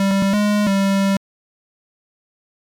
フリー効果音：ゲームオーバー
フリー効果音｜ジャンル：システム、ゲームオーバーなどにピッタリなシステム音！
gameover.mp3